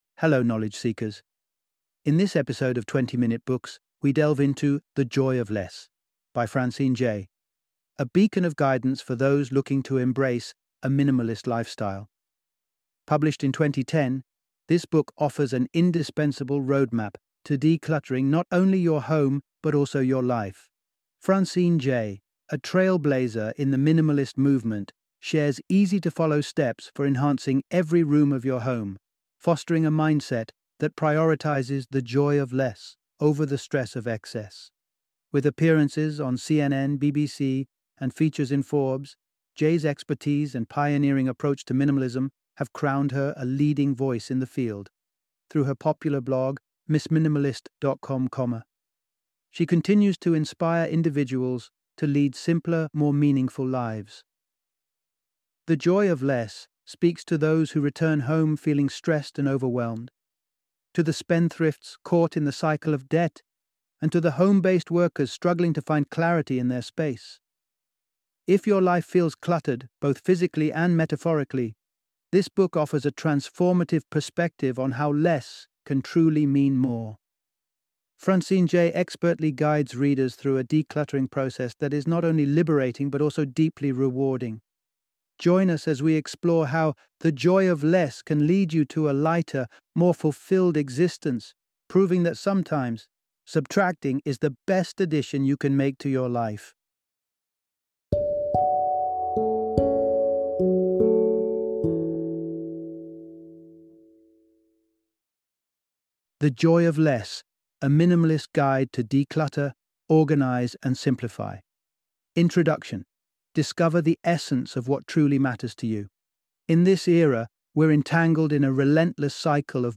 The Joy of Less - Audiobook Summary